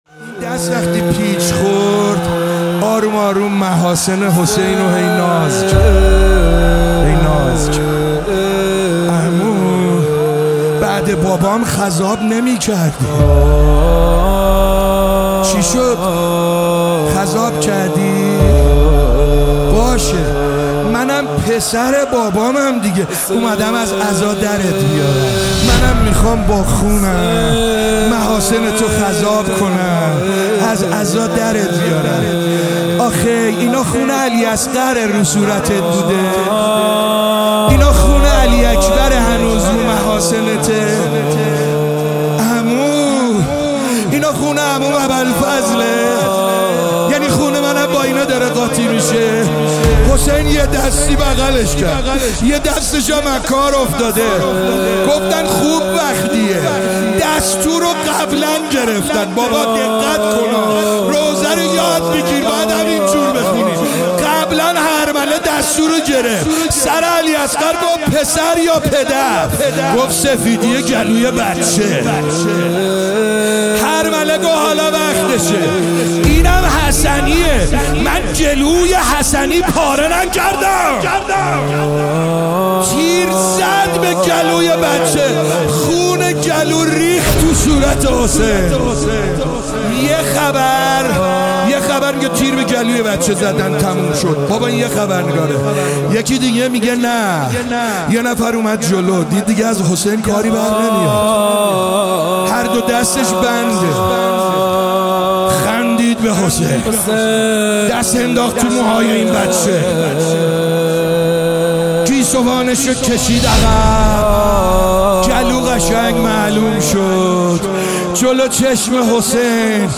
مداحی
محرم الحرام 1399 هیئت رزمندگان اسلام